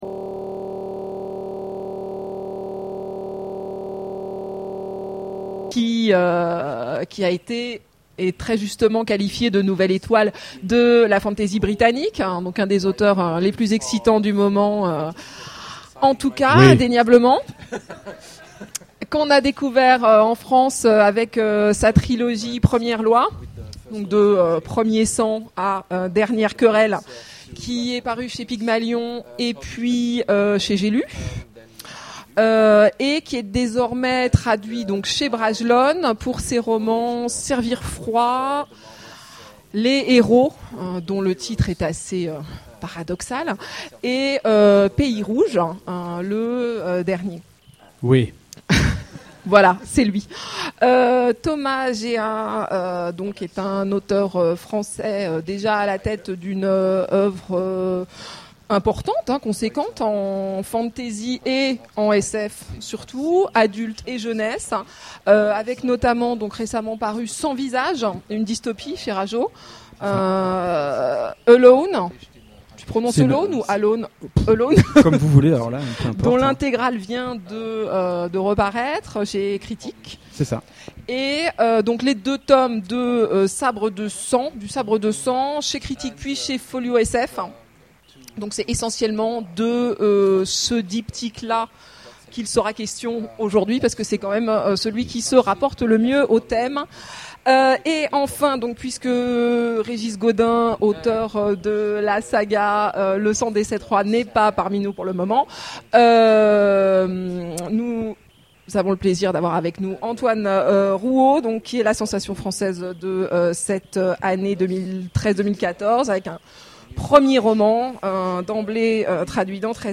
Imaginales 2014 : Conférence Chiens de guerre !
- le 31/10/2017 Partager Commenter Imaginales 2014 : Conférence Chiens de guerre !